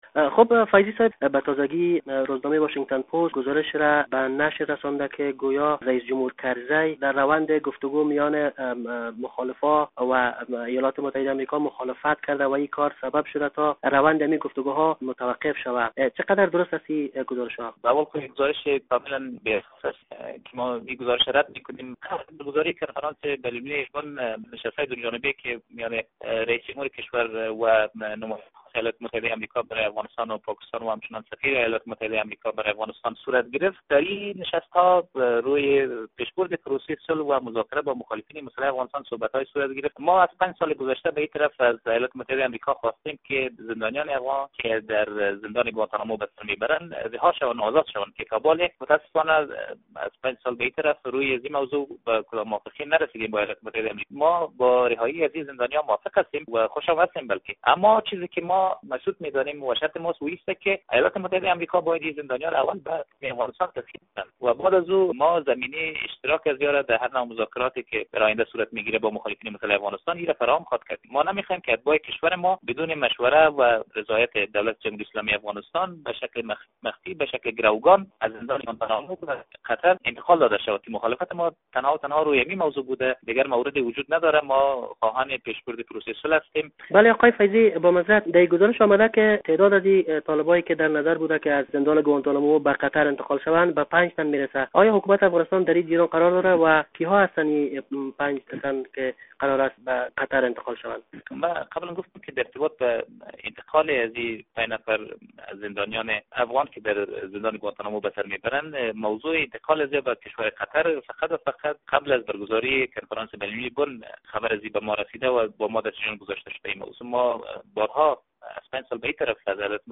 مصاحبه با ایمل فیضی در مورد گزارش اخیر واشنگتن پست